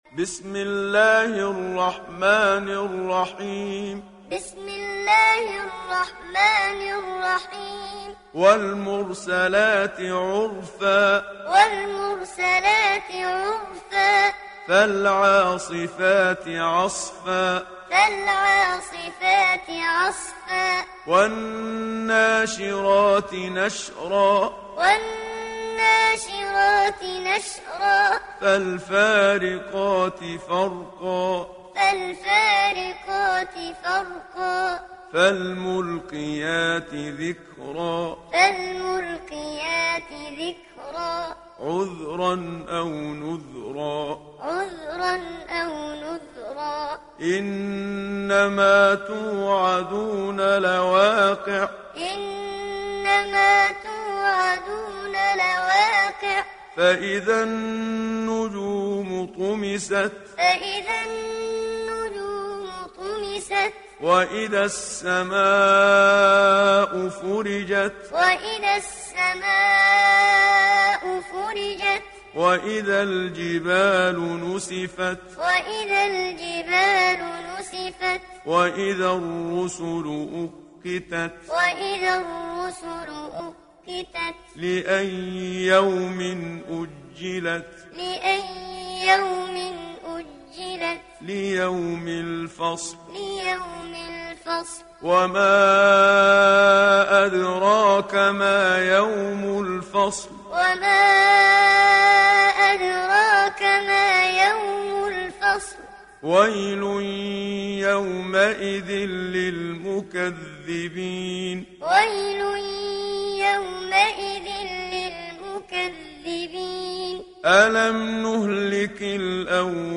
دانلود سوره المرسلات mp3 محمد صديق المنشاوي معلم روایت حفص از عاصم, قرآن را دانلود کنید و گوش کن mp3 ، لینک مستقیم کامل